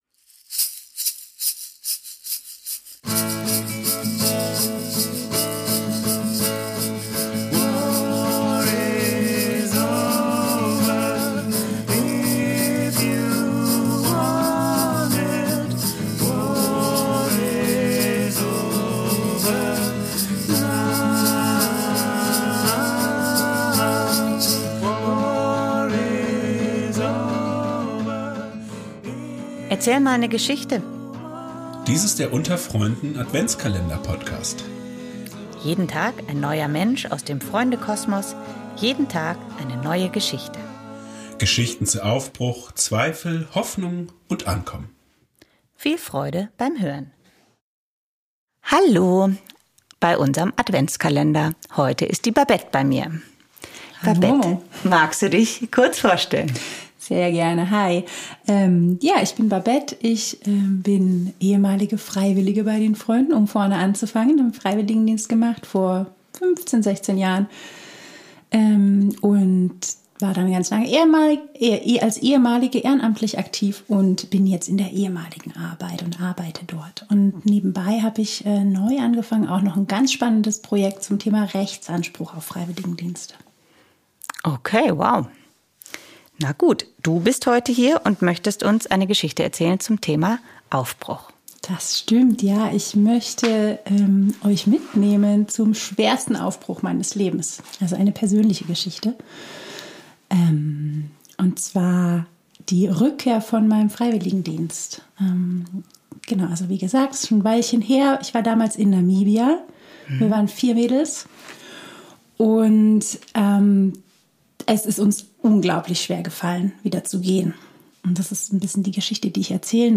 In unserem Adventskalender zum Hören erzählt jeden Tag eine andere